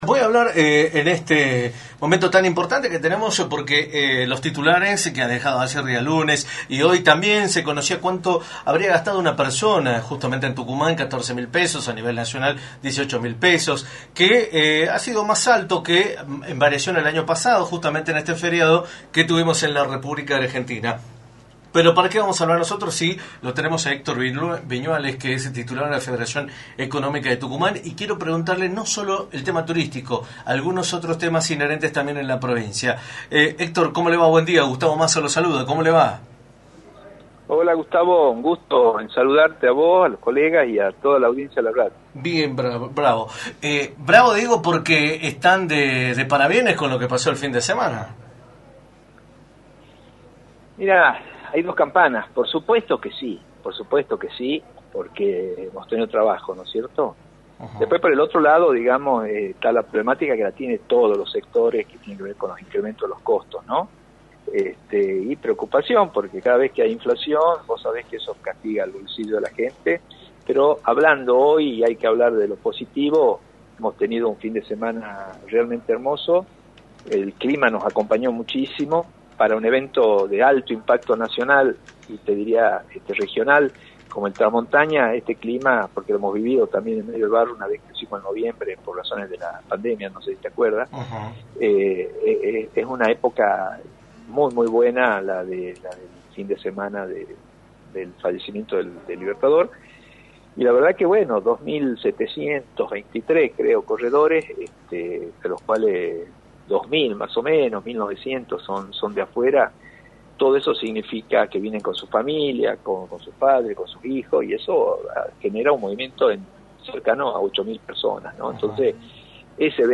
entrevista para “La Mañana del Plata”, por la 93.9.